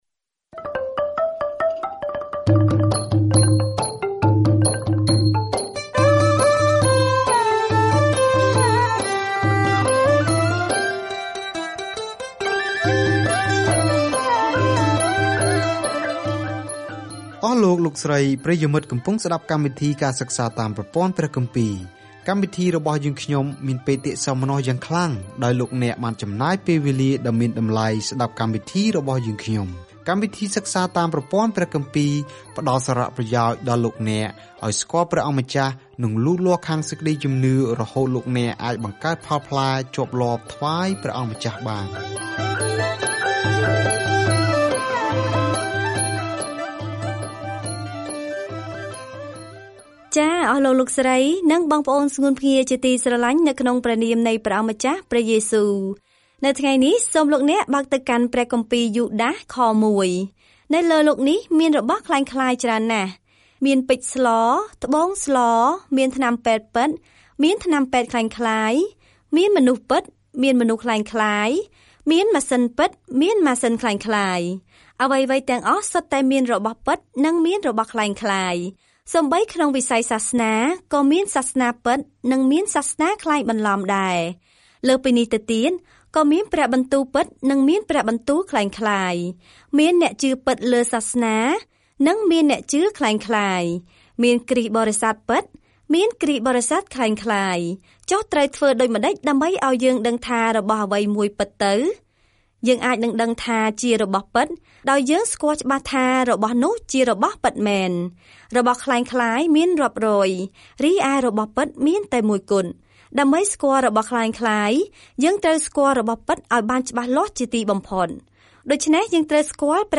“តស៊ូដើម្បីសេចក្តីជំនឿ” នេះនិយាយអំពីសំបុត្រខ្លីៗប៉ុន្តែដោយផ្ទាល់ពីយូដាសទៅកាន់គ្រីស្ទបរិស័ទប្រឆាំងនឹងគ្រូក្លែងក្លាយដែលបានចូលព្រះវិហារដោយមិនបានកត់សម្គាល់។ ការធ្វើដំណើរជារៀងរាល់ថ្ងៃតាមរយៈយូដាស ពេលអ្នកស្តាប់ការសិក្សាជាសំឡេង ហើយអានខគម្ពីរដែលបានជ្រើសរើសពីព្រះបន្ទូលរបស់ព្រះ។